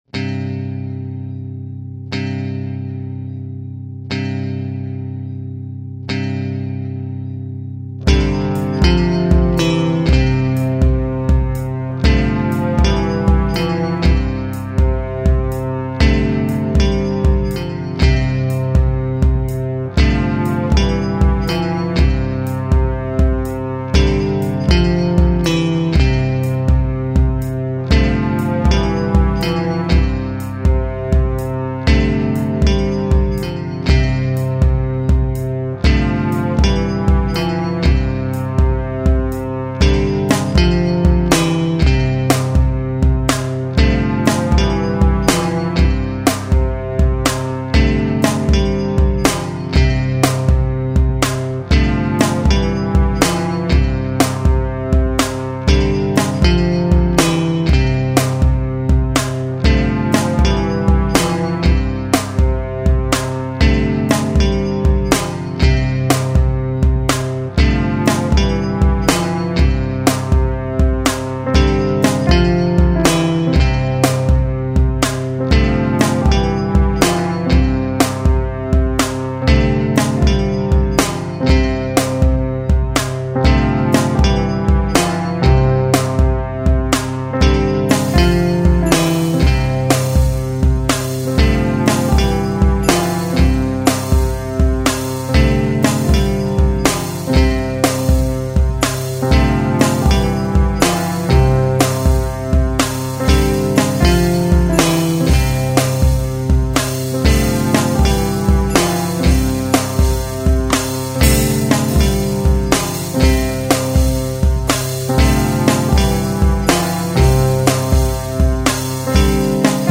The strangest of events can make you dust off the old keyboard and work on music again for the first time in 18 months.
I know it's drums are basic and unfinished; but much like the situation that caused this song, i just wanted it over with. all bs aside... this song is for you enjoy << back